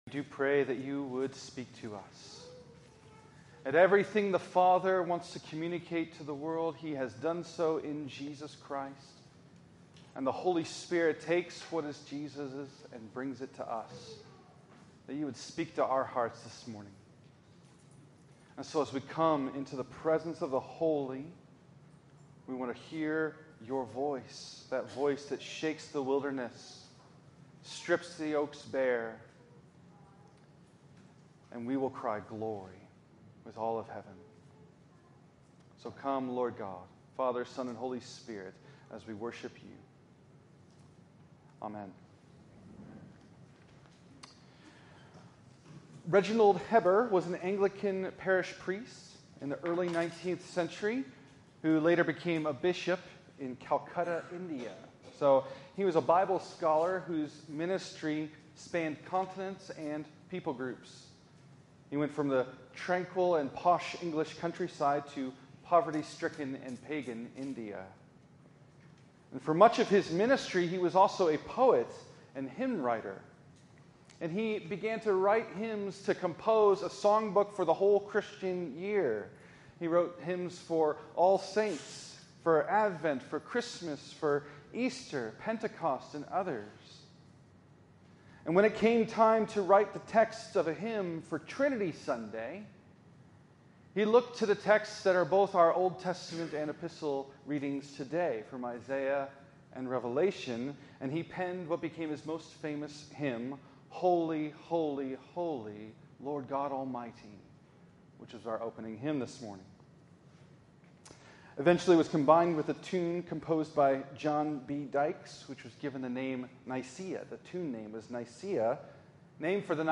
In this sermon from Trinity Sunday 2025